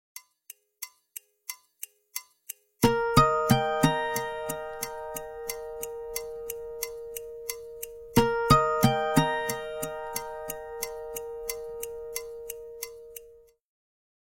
Index of /phonetones/unzipped/Nokia/6600-slide/Alert tones
Calendar alert 2.aac